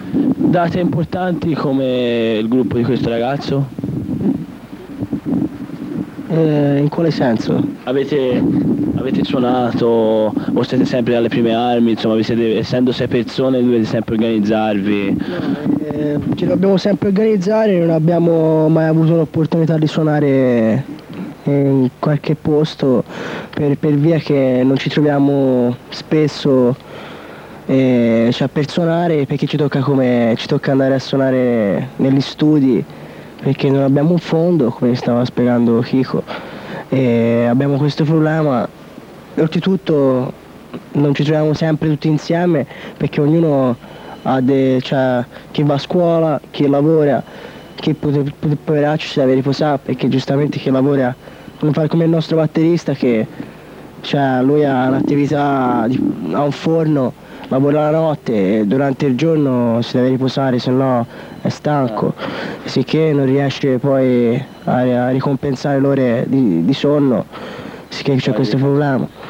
MusicaDroga Intervista.
monologo due